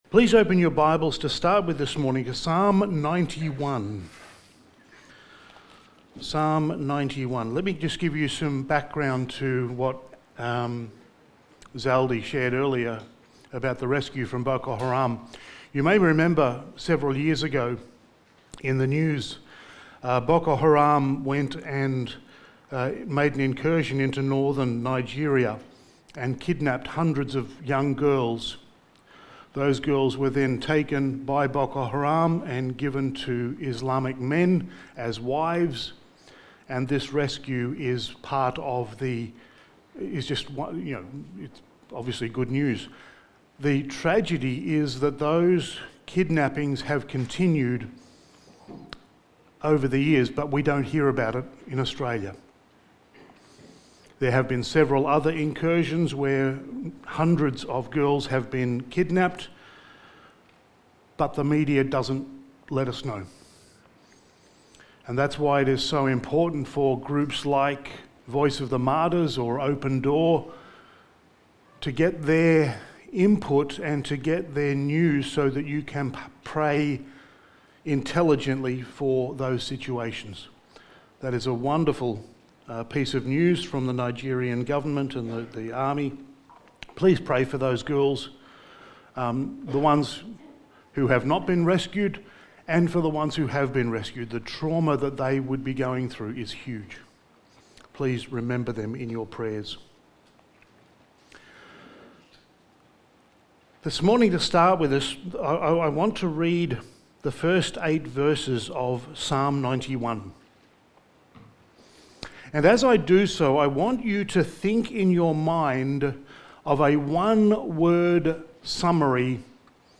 Sermon
Genesis Series Passage: Genesis 8:1-9:17 Service Type: Sunday Morning Sermon 13 « Toledot 3